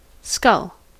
Ääntäminen
US : IPA : [ˈskəl]